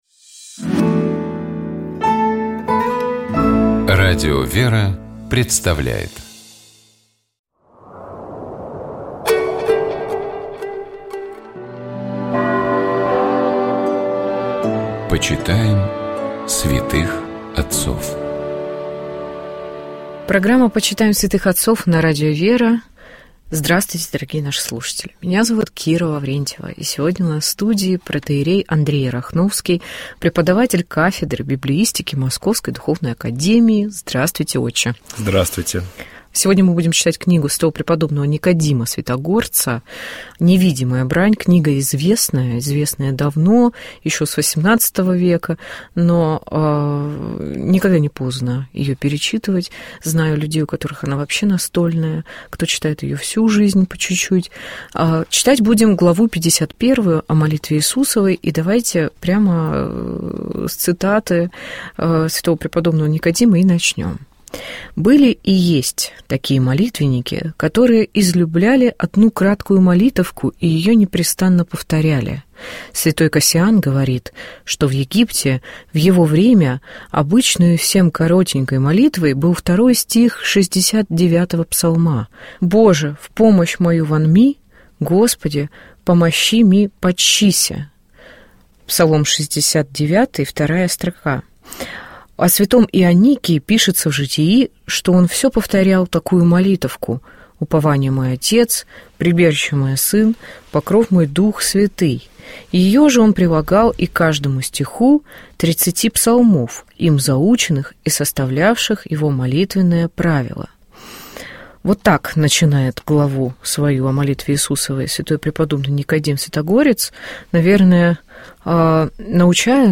Программа «Светлый вечер» — это душевная беседа ведущих и гостей в студии Радио ВЕРА.